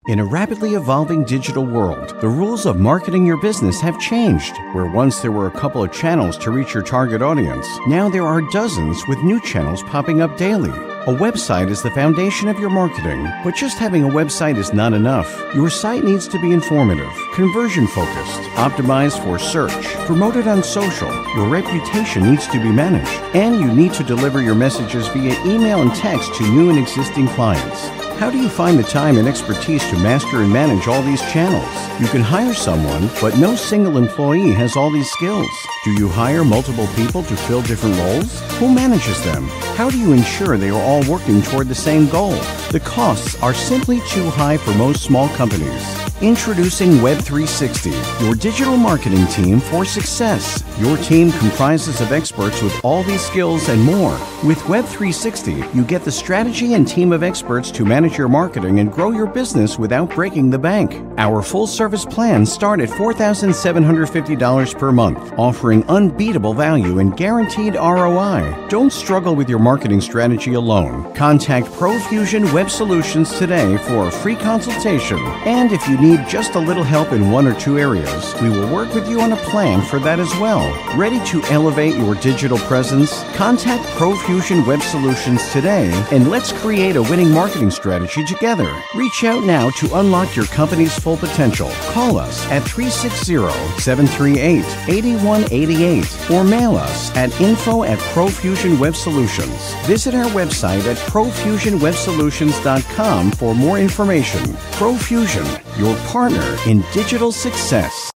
Anglais (américain) doubleur
Vidéos explicatives